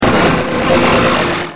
P3D-Legacy / P3D / Content / Sounds / Cries / 357.wav